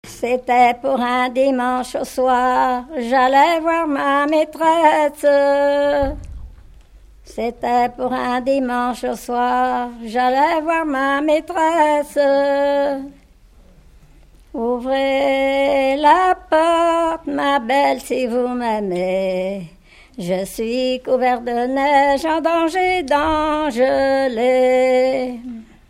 Genre laisse
Enquête Arexcpo en Vendée-C.C. Yonnais
Pièce musicale inédite